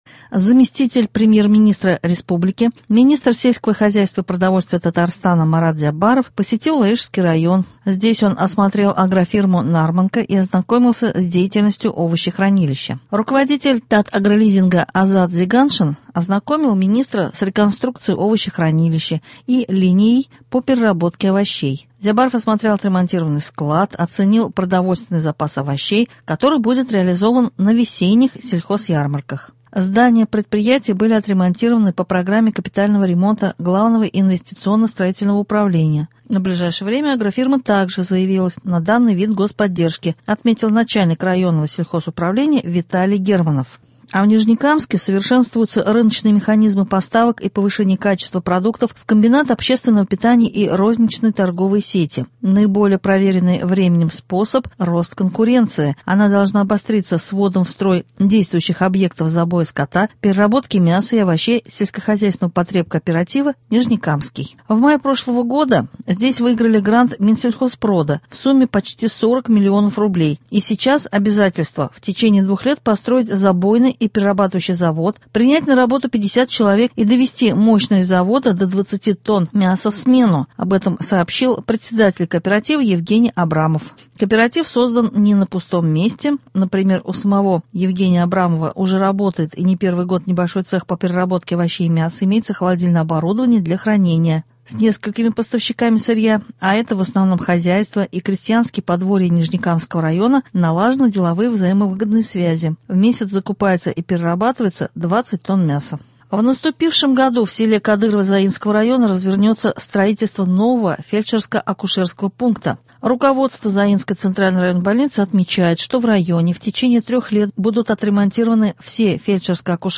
Новости (11.01.21)